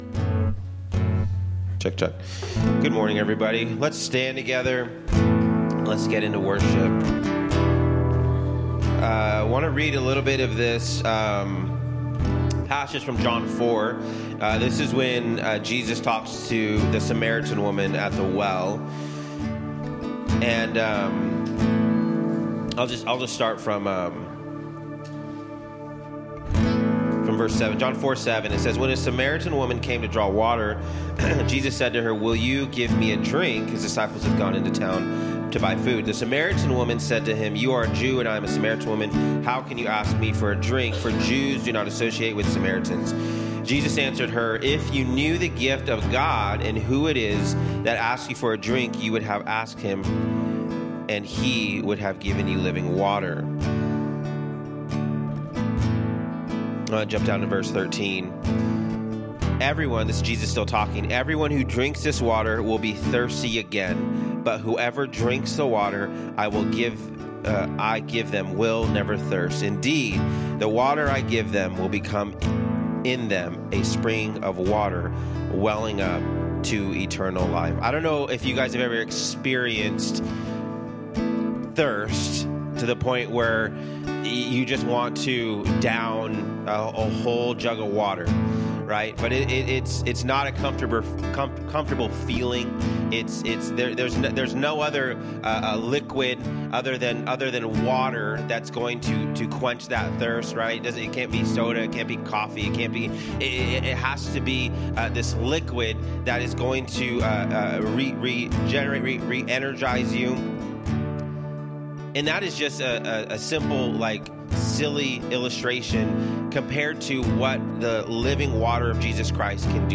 From Series: "Sermon"